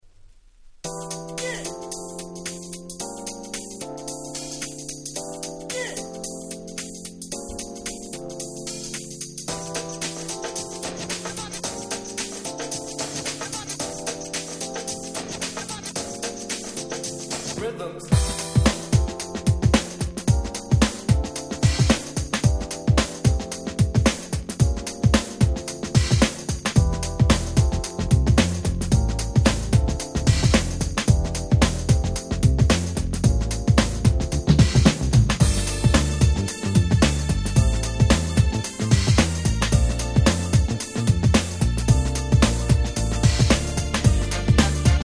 ※試聴ファイルは他の盤から録音してあります。
最強のUK Soul。
この疾走感、最高としか言い様がありません！！